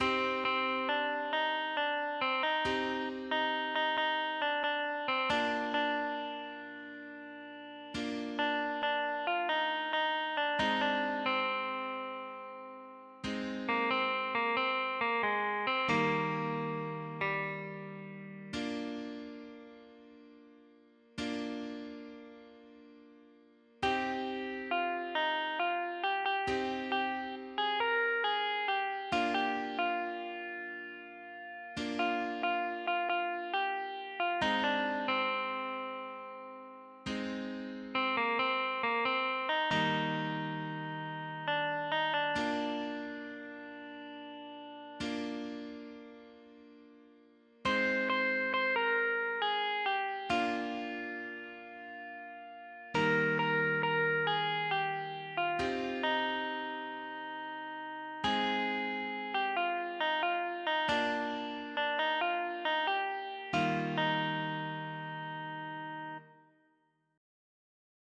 (Via Crucis cantado)